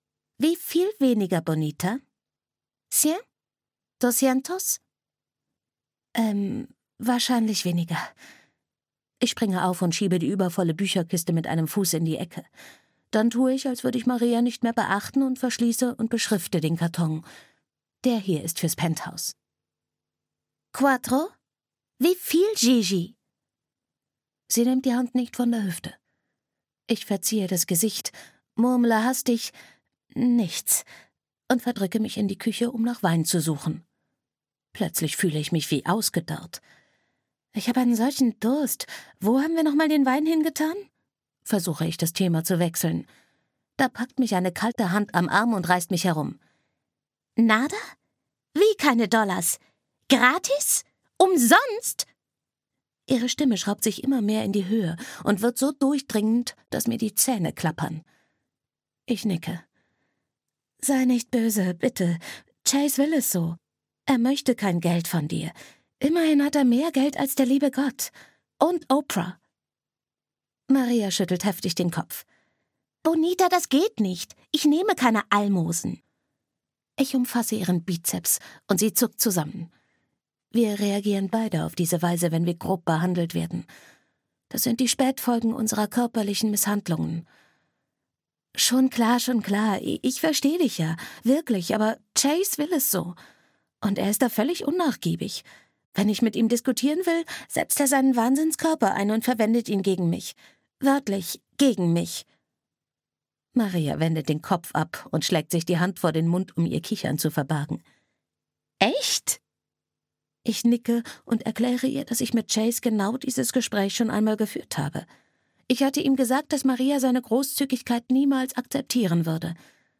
Trinity - Gefährliche Nähe (Die Trinity-Serie 2) - Audrey Carlan - Hörbuch